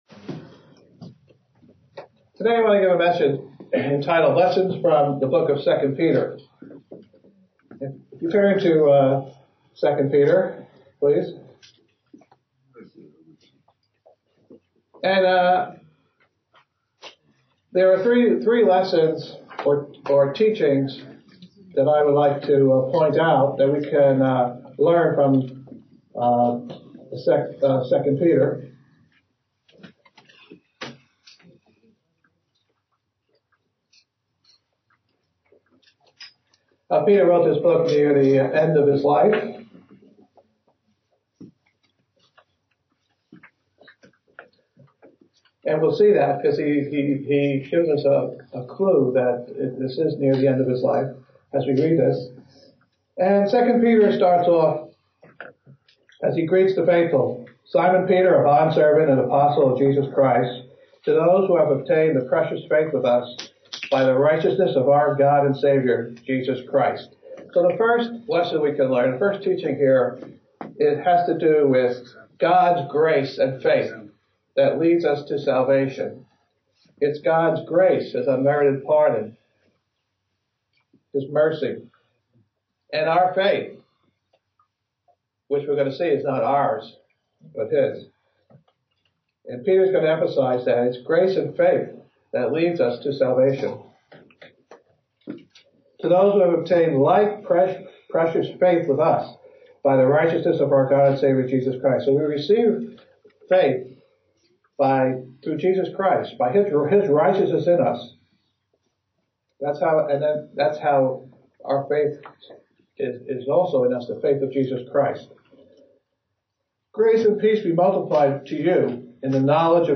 Given in New York City, NY